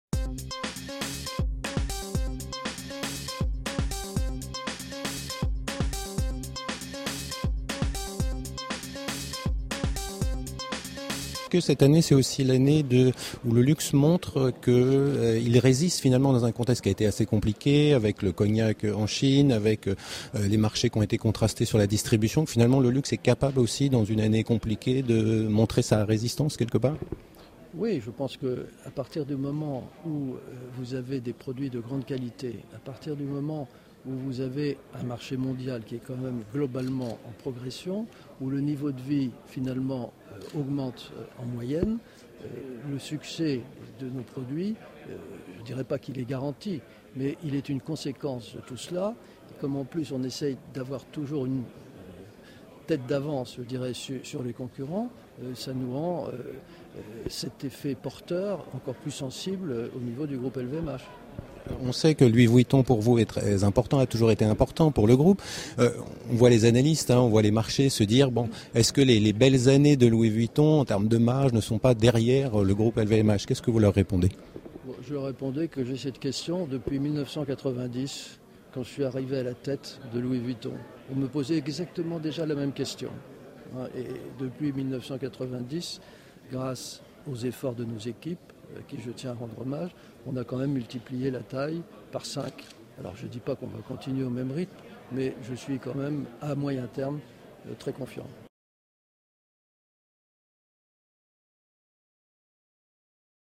Bernard Arnault Pdg de LVMH nous a accordé après la conférence de presse – analystes, quelques minutes pour répondre à deux questions : la résistance du luxe dans un contexte économique moins favorable et la question centrale avec la marque Louis Vuitton, serait-elle capable de délivrer toujours de hauts niveaux de résultats comme au cours des dernières années.
Category: L'INTERVIEW